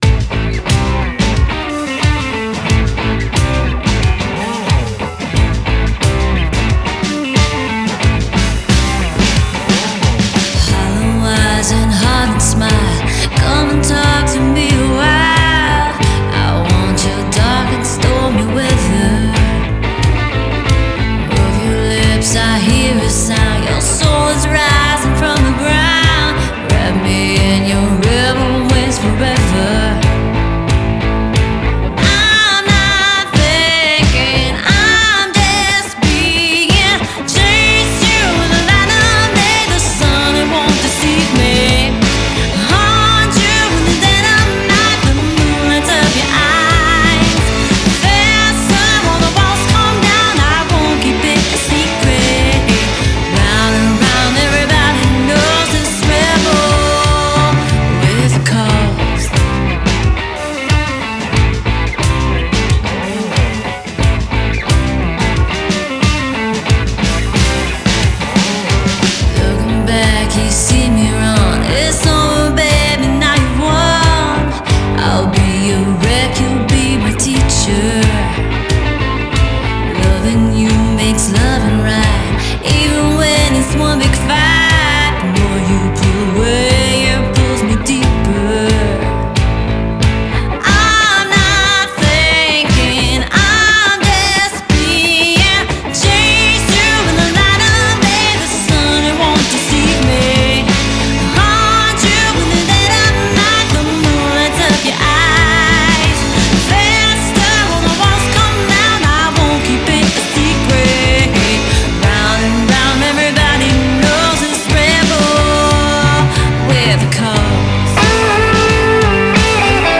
Killer Vocals, Killer Guitar, Killer Rock !!
Genre: Blues, Blues Rock